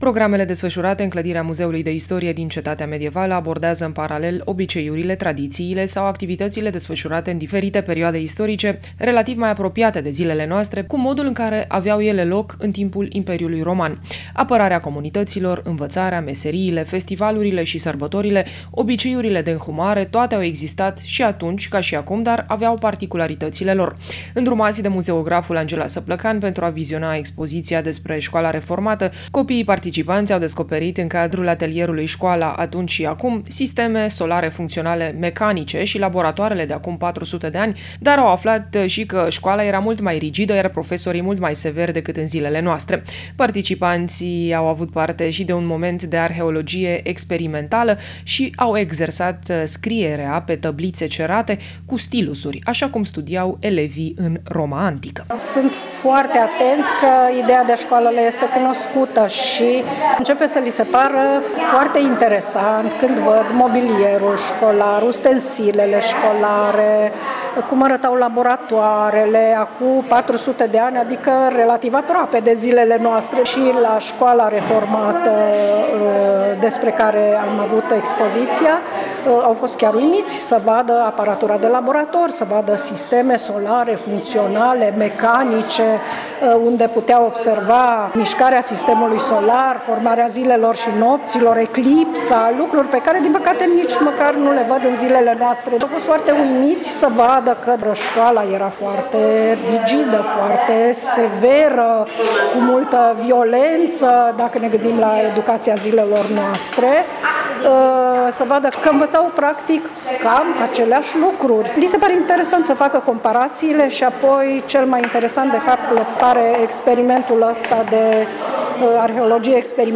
15-iulie-Copilarii-Reportaj-Vacanta-la-muzeu.mp3